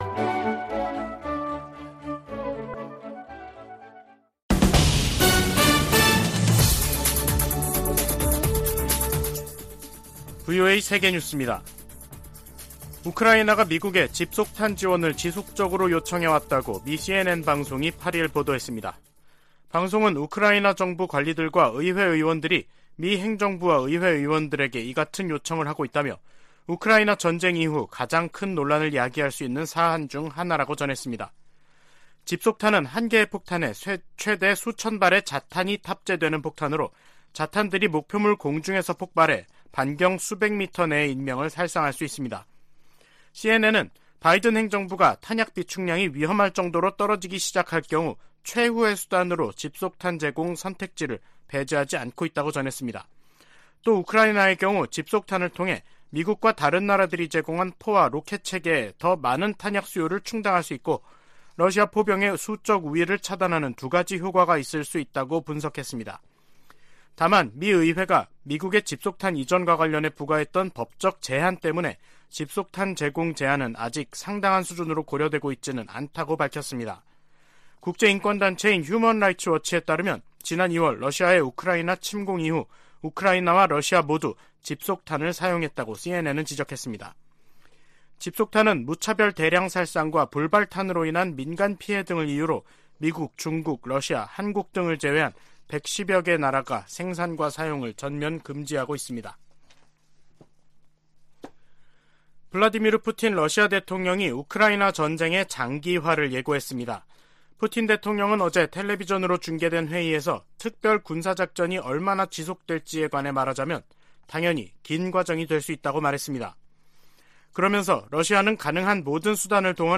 VOA 한국어 간판 뉴스 프로그램 '뉴스 투데이', 2022년 12월 8일 3부 방송입니다. 미국은 북한 정권의 계속된 도발에 대응해 한국, 일본 등과 연합 훈련과 협력을 계속할 것이라고 백악관 고위관리가 밝혔습니다. 오는 12～13일 인도네시아 자카르타에서 미한, 한일, 미한일 북 핵 수석대표 협의가 열립니다.